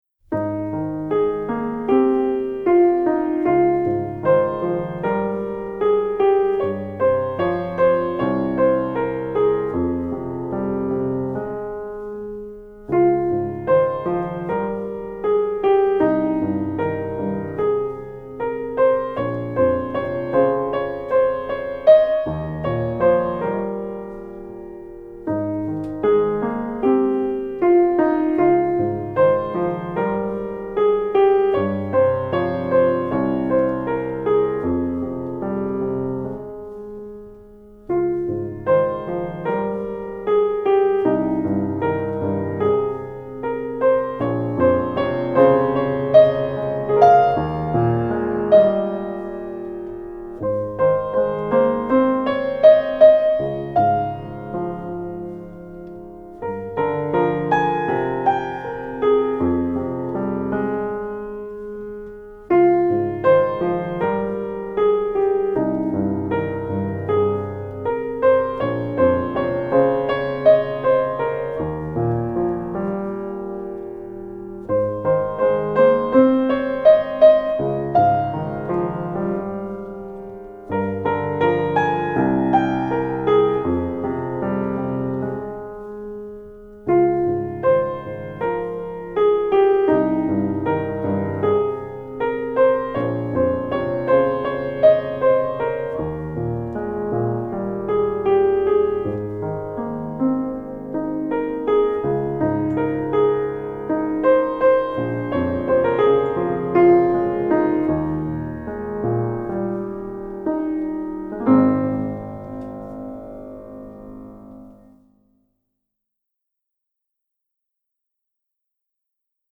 Instrumental arrangement